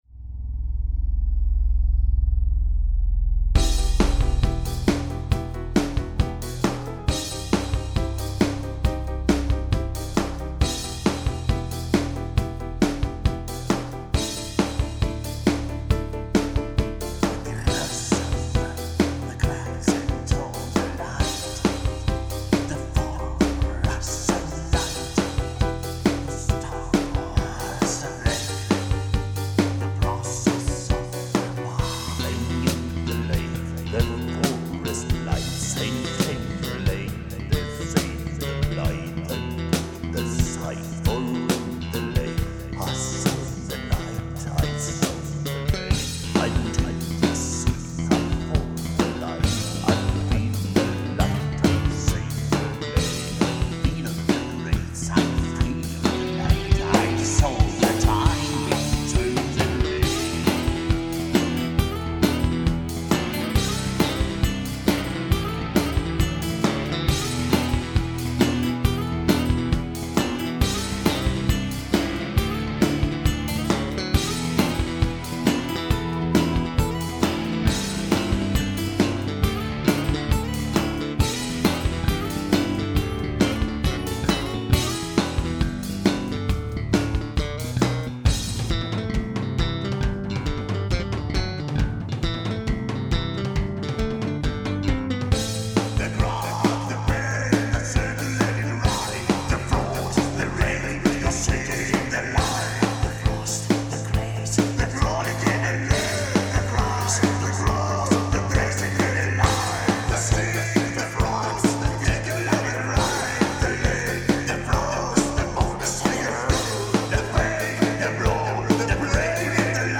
...With a metal flavor, for the fun of it!